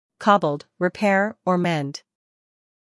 英音/ ˈkɒbld / 美音/ ˈkɑːbld /